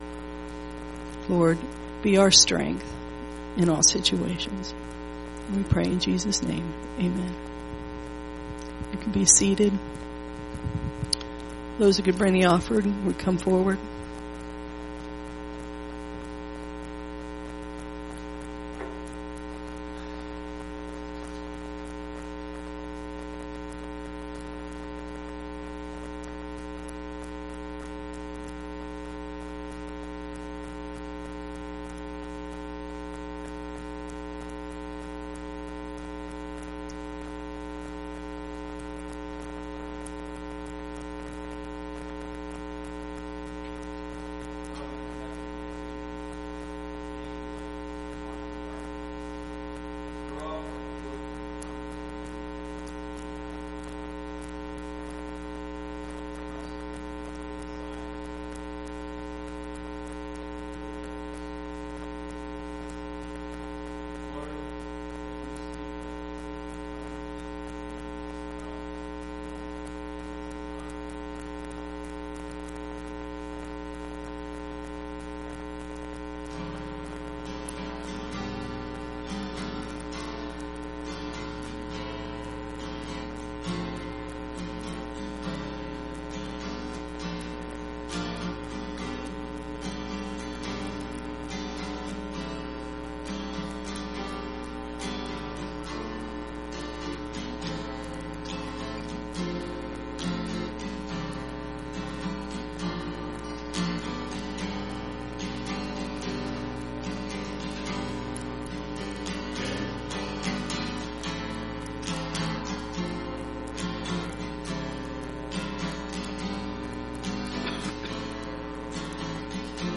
sermon-5-27-18.mp3